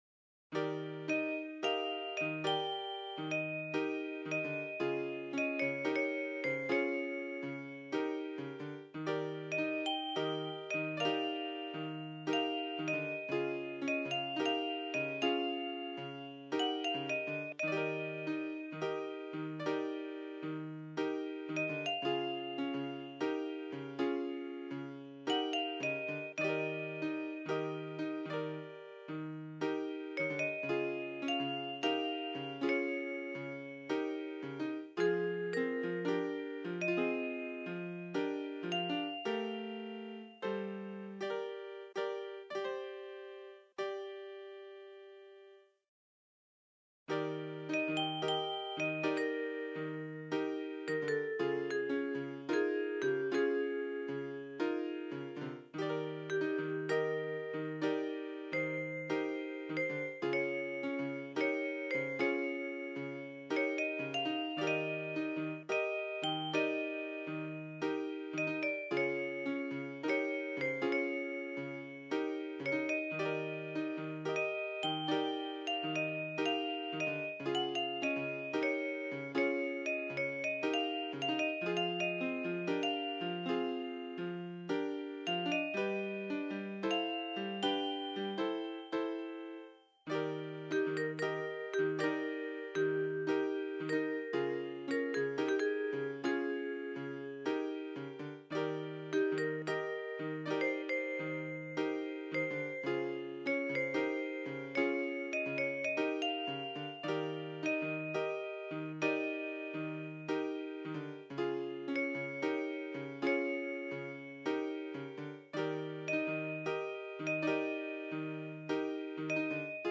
Relaxing Melody 2
Nice little melody,just like simple tunes sometimes instead of complex.
relaxingmelody2_1.ogg